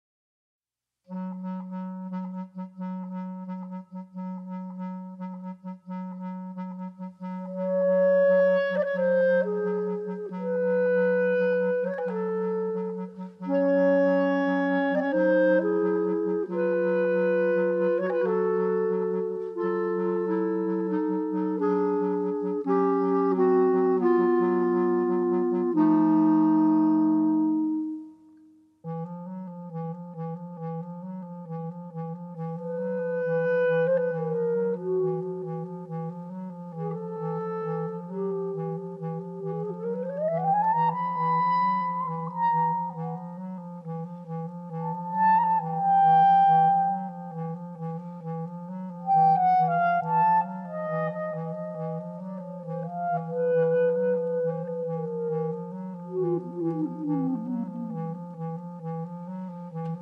Clarinet